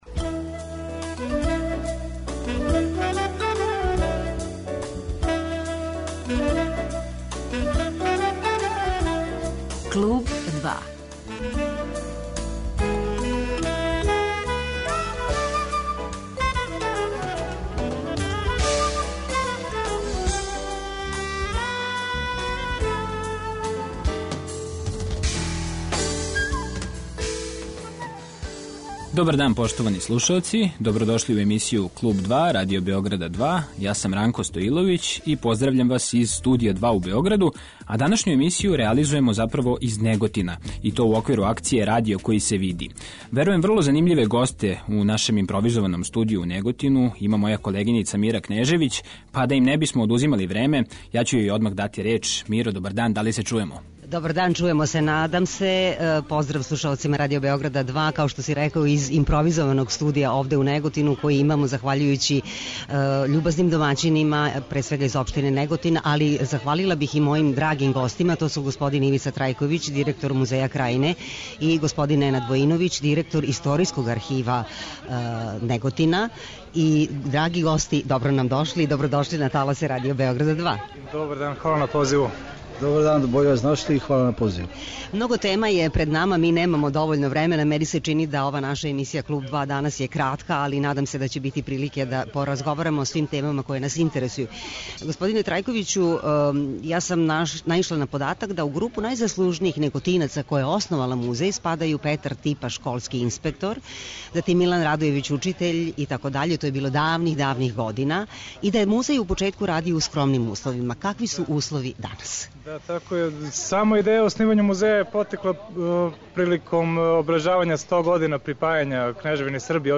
'Клуб 2' ће ове среде бити емитован из Неготина у оквиру акције 'Радио који се види', у којој учествује и Радио Београд 2.